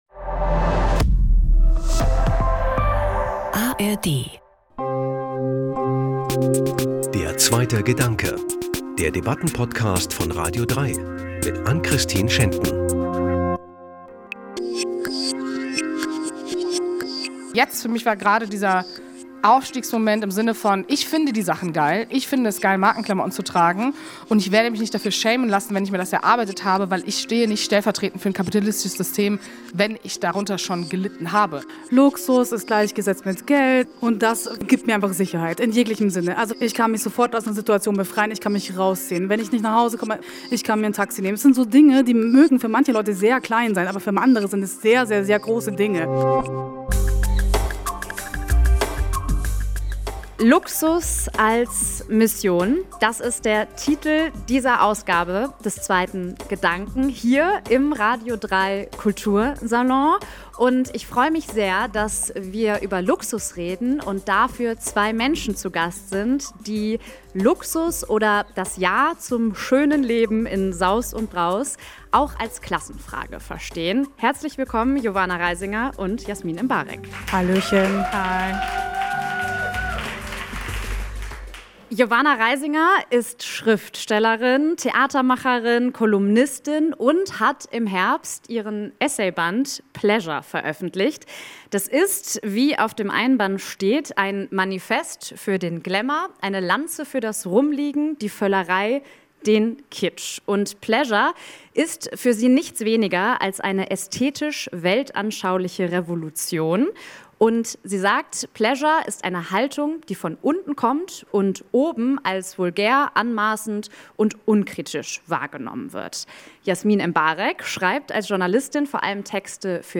Aufzeichnung vom 20. November 2024 im radio3 Kultursalon – der rbb Dachlounge.